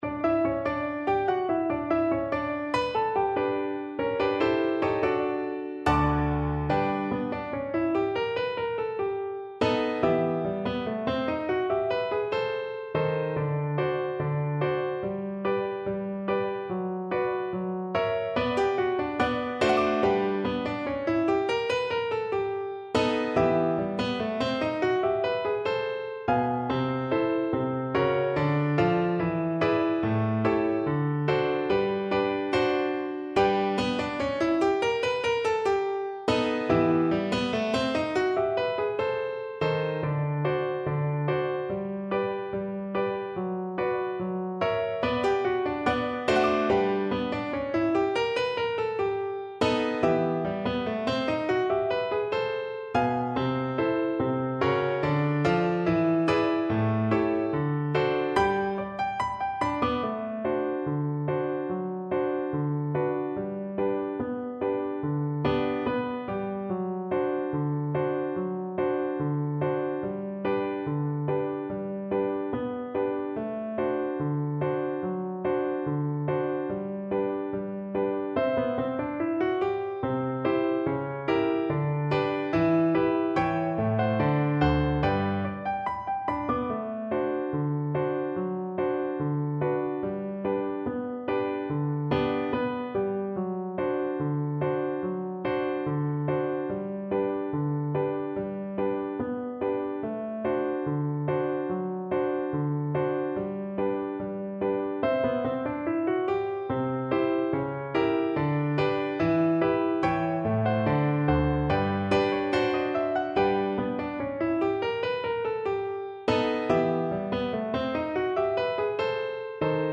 Play (or use space bar on your keyboard) Pause Music Playalong - Piano Accompaniment Playalong Band Accompaniment not yet available transpose reset tempo print settings full screen
Double Bass
2/4 (View more 2/4 Music)
Slow march tempo = 72
G major (Sounding Pitch) (View more G major Music for Double Bass )
Jazz (View more Jazz Double Bass Music)